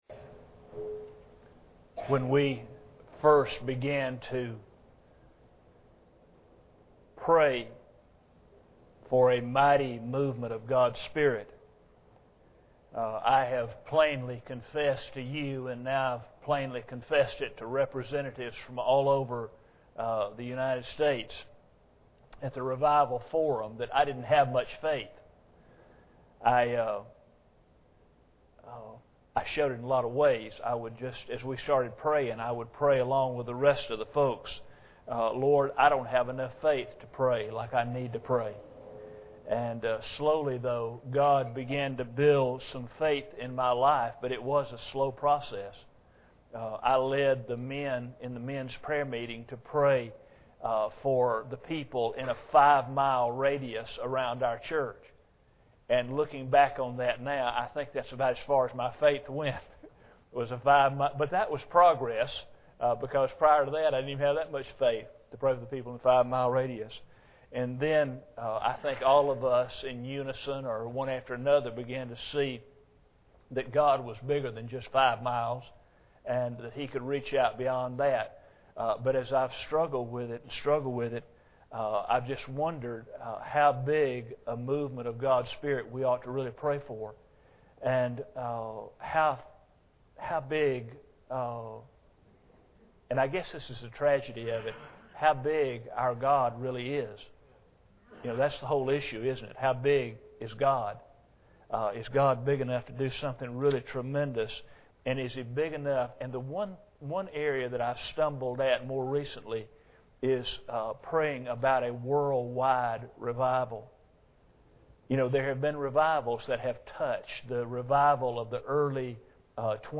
Testimonies
Preacher: CCBC Members | Series: General